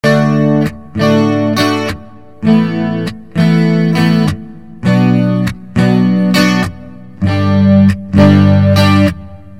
吉他小子原声和弦第一部分
描述：漂亮的原声和弦
Tag: 100 bpm Acoustic Loops Guitar Electric Loops 1.62 MB wav Key : Unknown